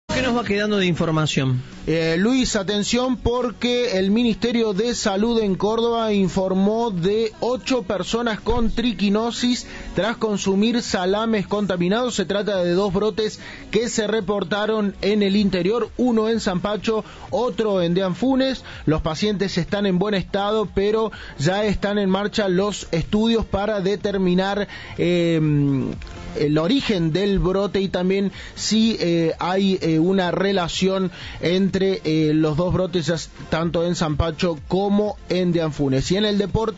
En diálogo con Cadena 3 Javier Ocanto, secretario de Salud de Deán Funes, dijo que el brote se está investigando y que en la localidad serían 4 las personas afectadas.